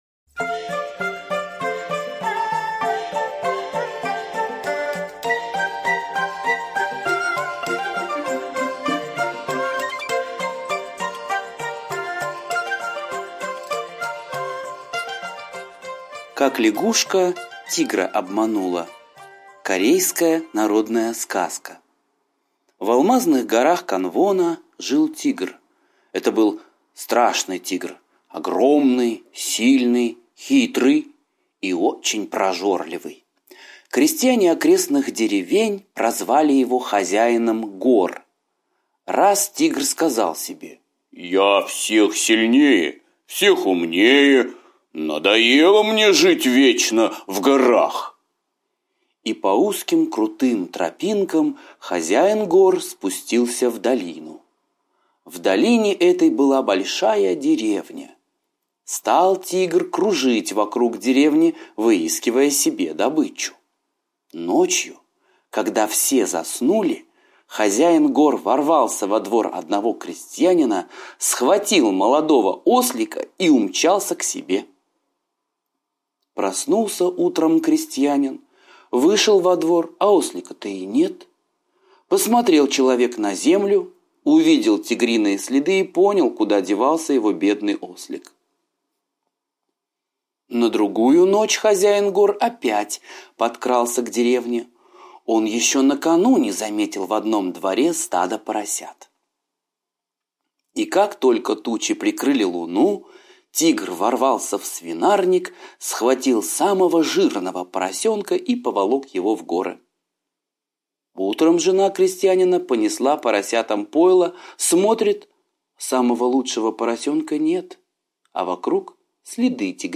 Как Лягушка Тигра обманула - восточная аудиосказка - слушать онлайн